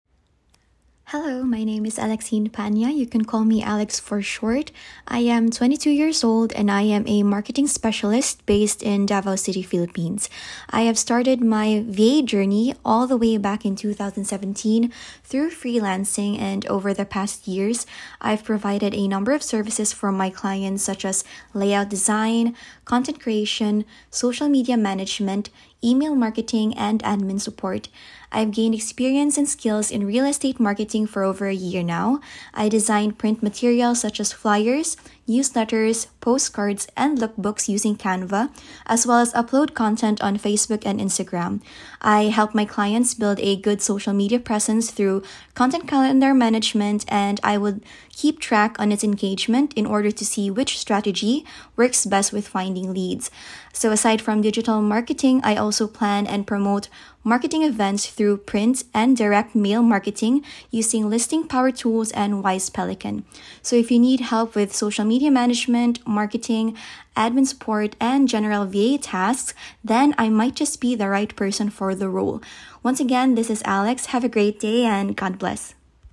Self Introduction
Self-Introduction-1.mp3